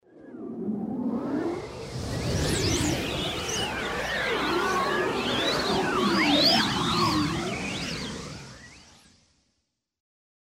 Звуки волшебства
• Качество: высокое
Шепот сказочного ветра